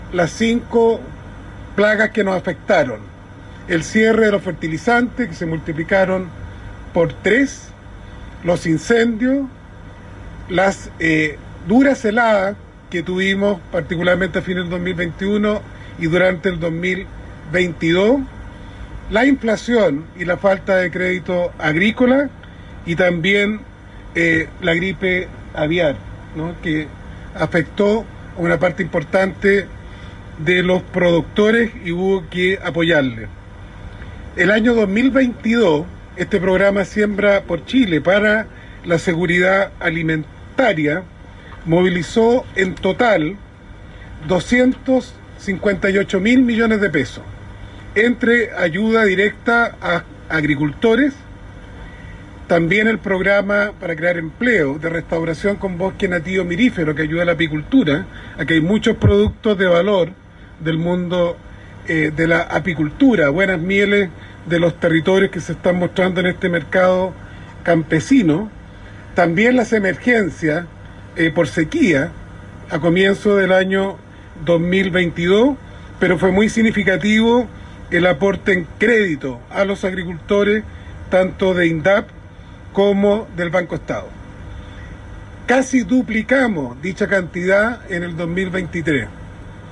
Audio Ministro de Agricultura, Esteban Valenzuela.